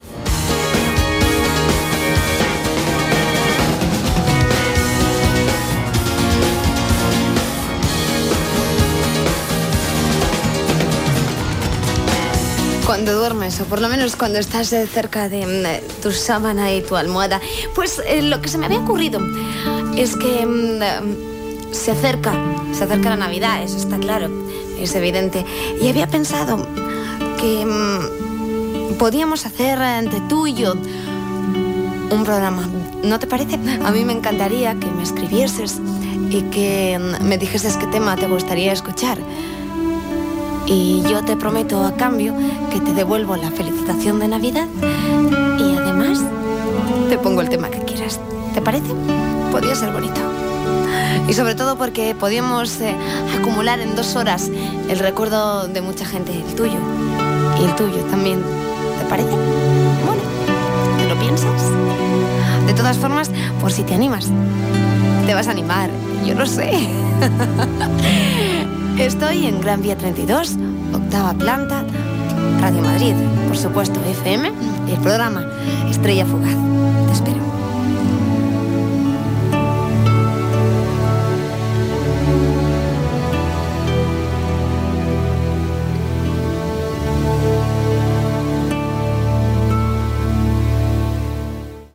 FM